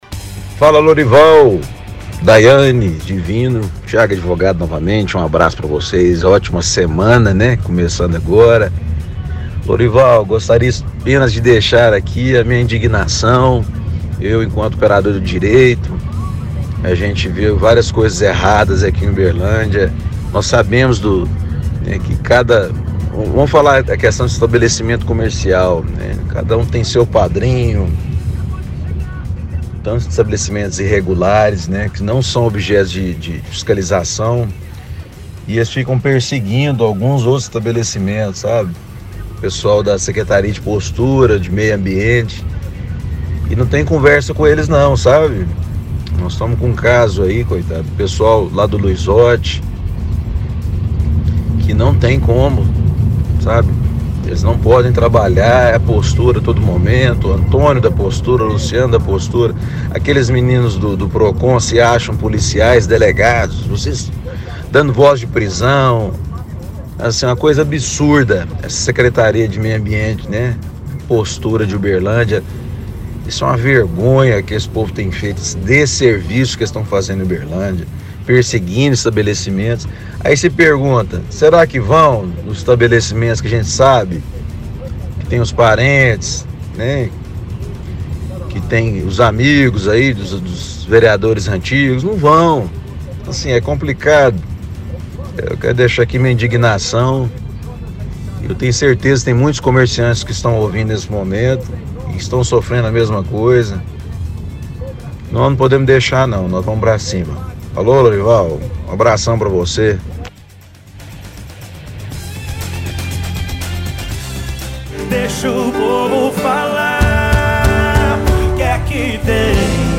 – Ouvinte reclama do setor de posturas da cidade dizendo que muitos comerciantes são fiscalizados e não podem trabalhar.